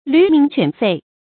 驢鳴犬吠 注音： ㄌㄩˊ ㄇㄧㄥˊ ㄑㄨㄢˇ ㄈㄟˋ 讀音讀法： 意思解釋： 如同驢叫狗咬一般。形容文字言語拙劣。